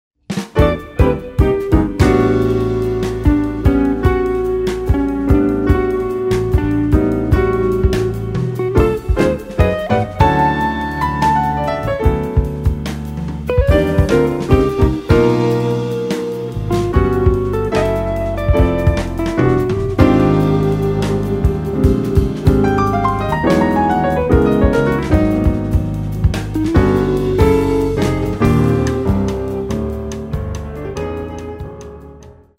Quartet